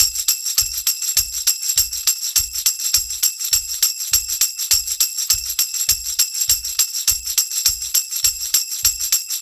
TR PERCS 3.wav